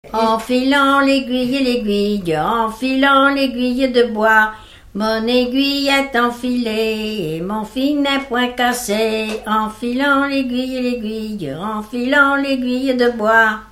Enfantines - rondes et jeux
Pièce musicale inédite